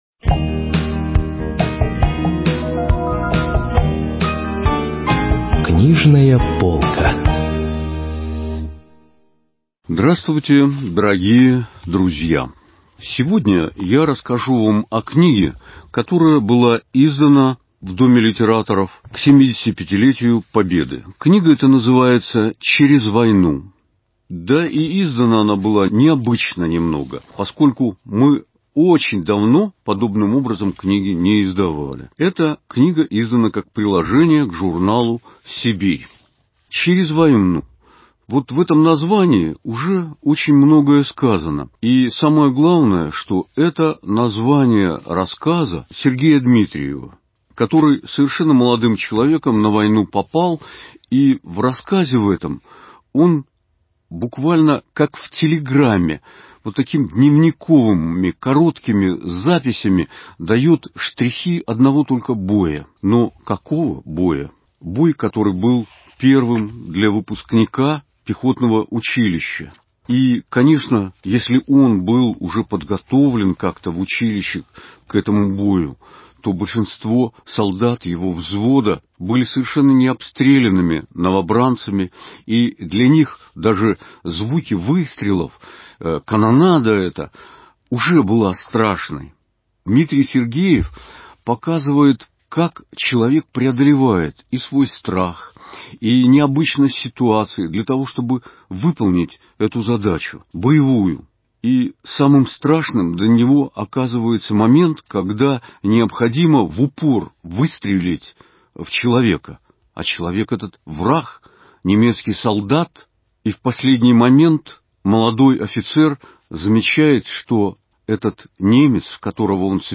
Вашему вниманию радио-презентация книги, вышедшей в качестве приложения к журналу «Сибирь» в издательстве «Сибирская книга» в 2020 г., составленной из повестей и рассказов о Великой Отечественной войне.